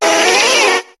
Cri de Rapasdepic dans Pokémon HOME.